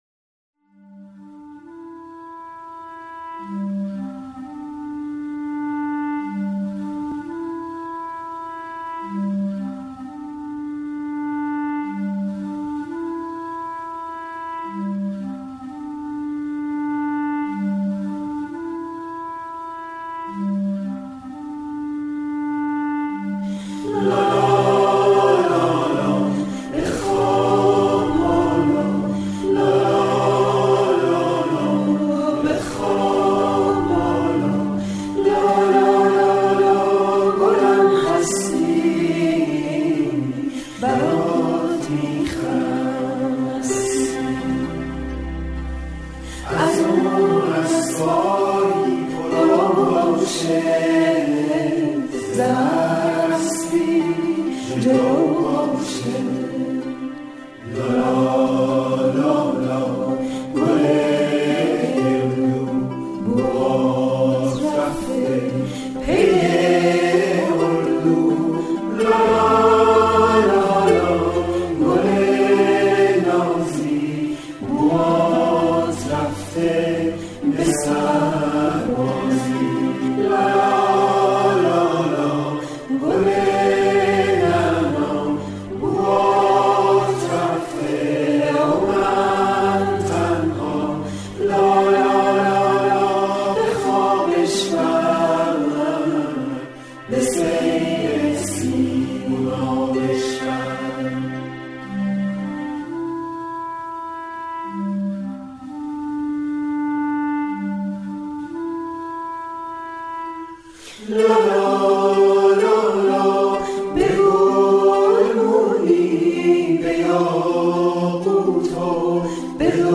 آهنک لالایی